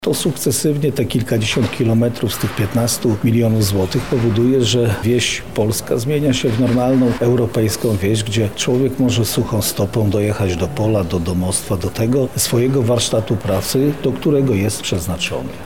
– mówi Marszałek Województwa Lubelskiego, Jarosław Stawiarski.